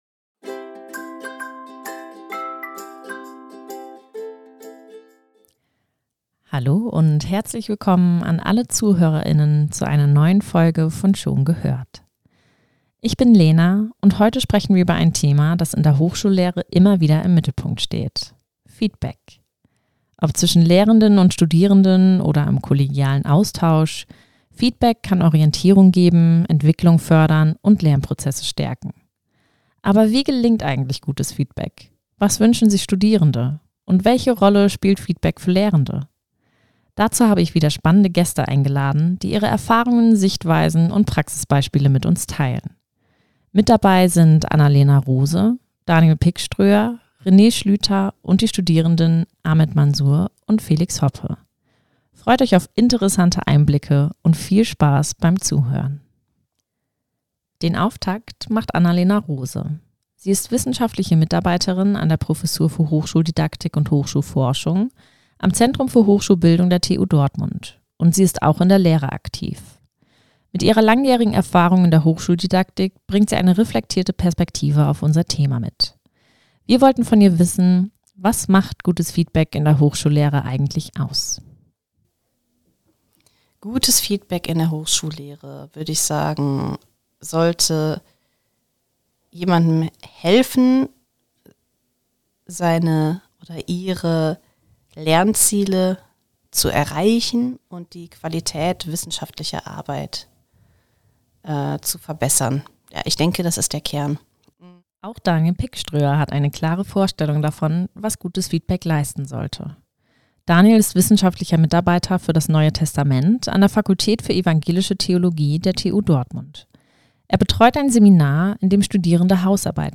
Unsere studentischen Mitarbeiterinnen sprechen mit Lehrenden und Studierenden der TU Dortmund über gelungene Feedbackpraxis, Herausforderungen und die Frage, wie eine konstruktive Feedbackkultur in der Hochschule aussehen kann.